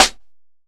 Snares
DillaInThisSnare.wav